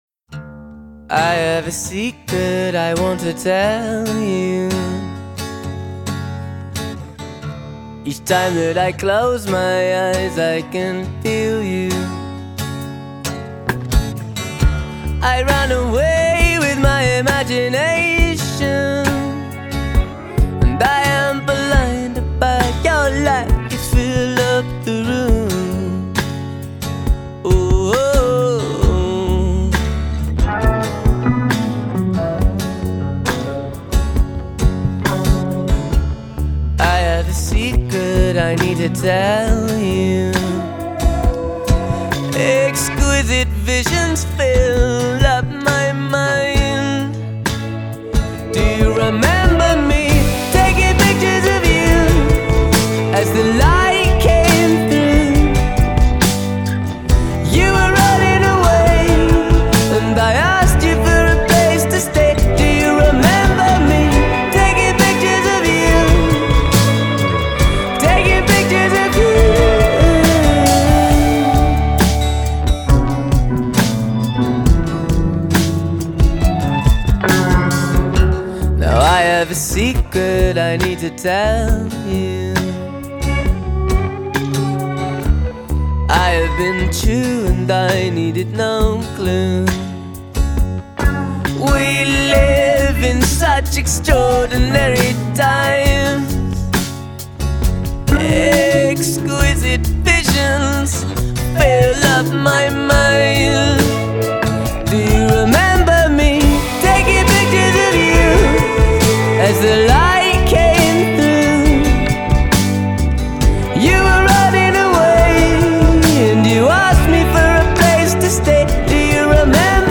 Style: Indie rock